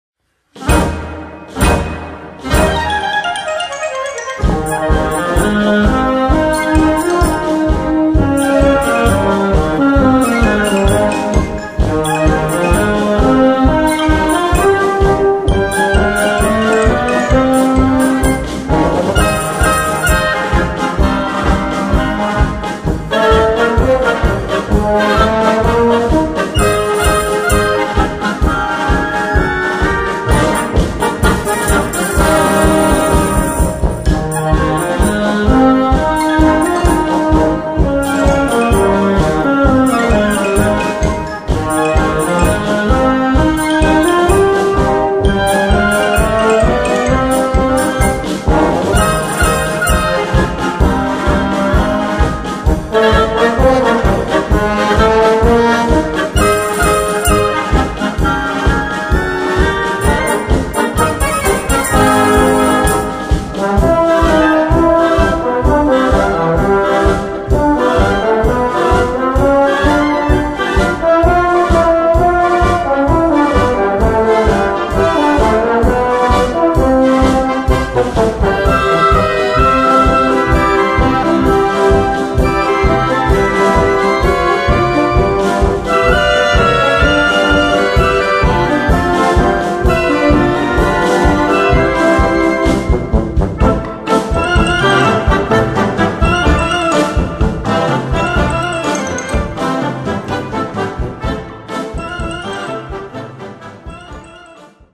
Konzertstück für Blasorchester
Besetzung: Blasorchester